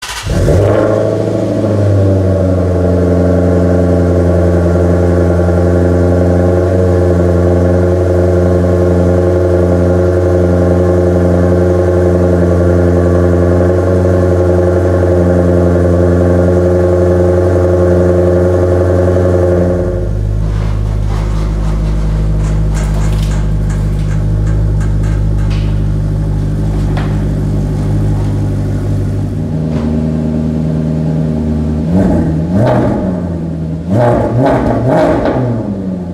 Por supuesto, también produce el legendario sonido que solo puede provenir de un V8 atmosférico, totalmente italiano